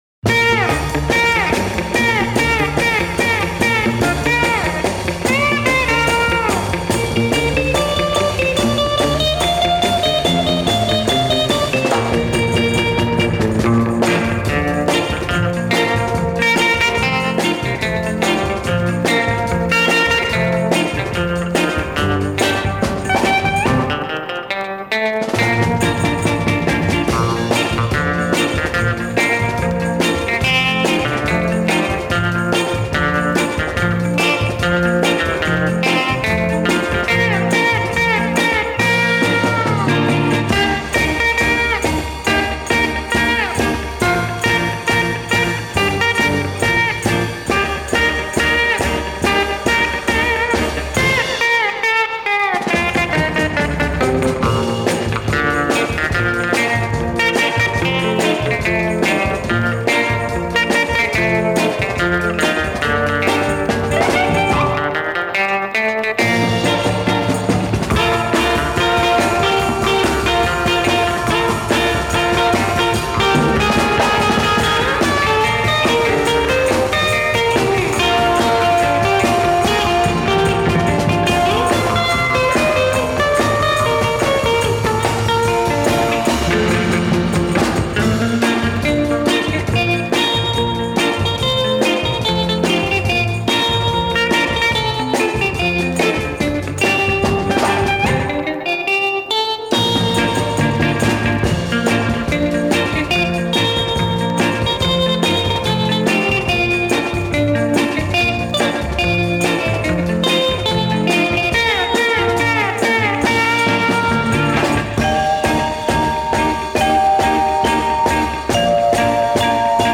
two yuletide tracks from the Taiwanese 60s instro album